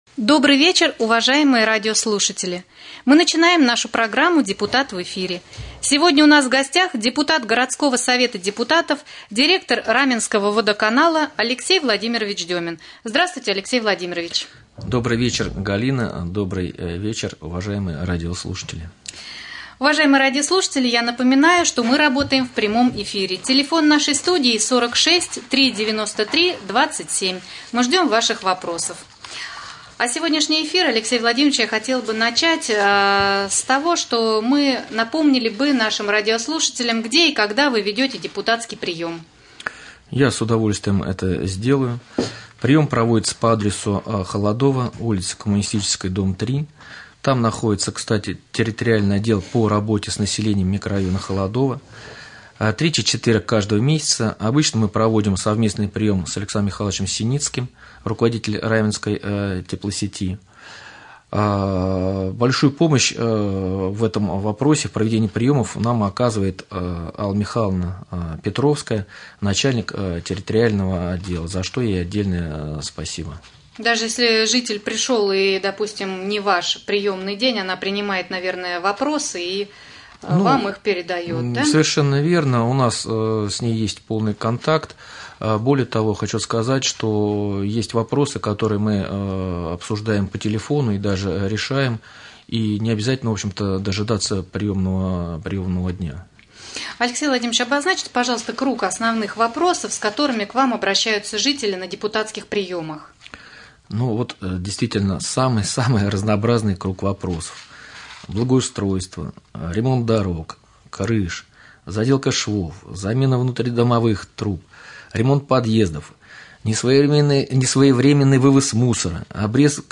1.Новостной блок
2.Прямой эфир.